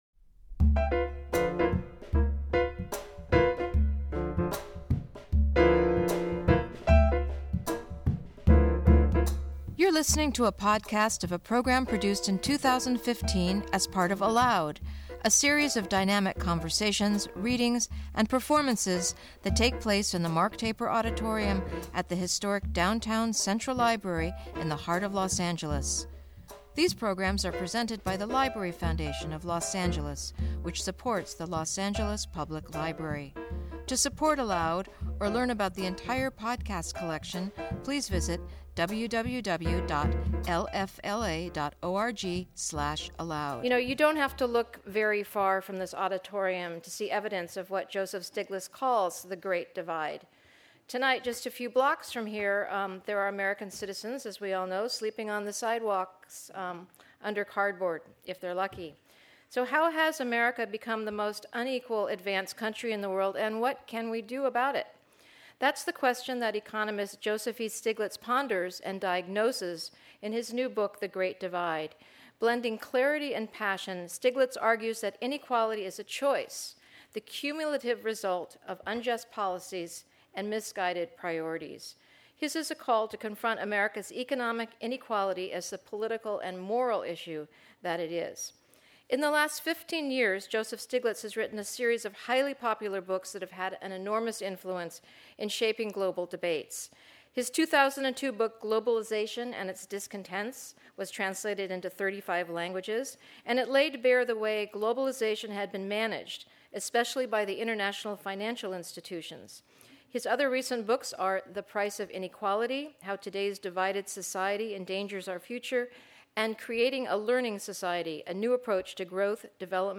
Joseph Stiglitz In Conversation